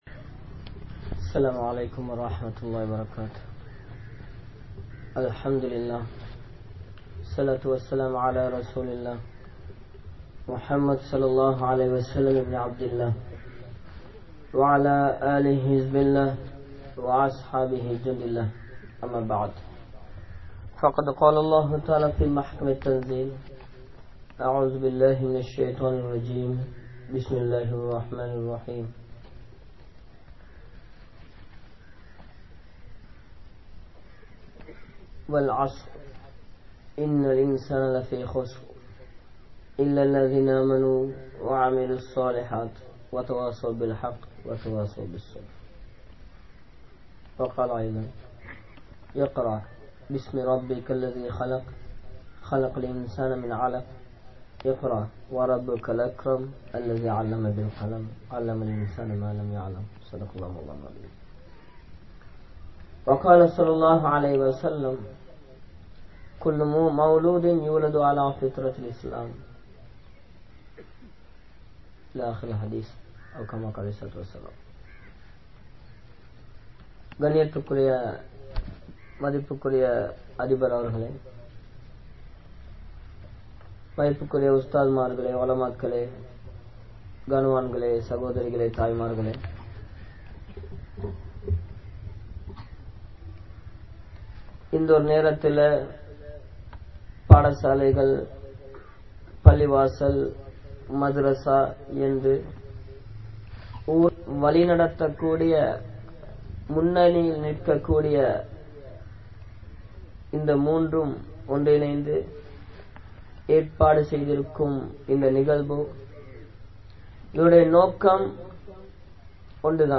Pillaihal Seeraliya Kaaranam Yaar?(பிள்ளைகள் சீரழிய காரணம் யார்?) | Audio Bayans | All Ceylon Muslim Youth Community | Addalaichenai